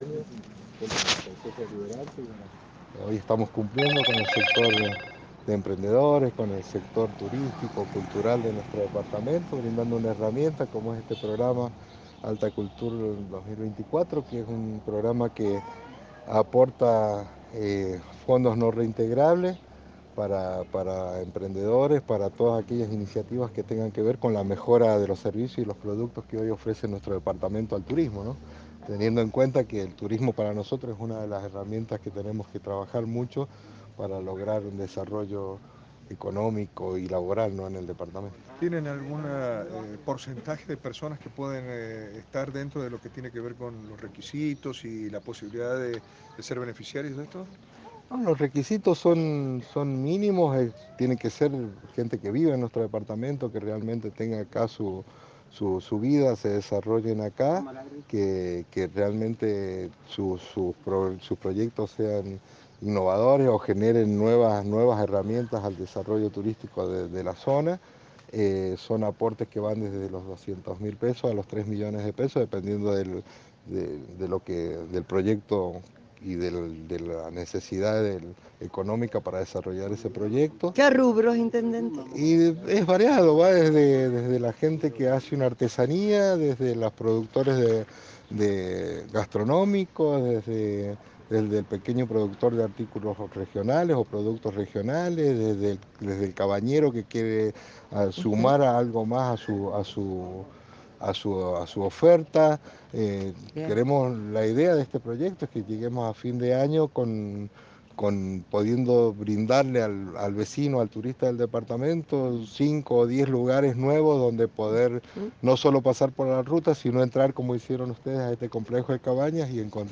Palabra de David Dominguez intendendte de Ullum: